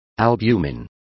Complete with pronunciation of the translation of albumin.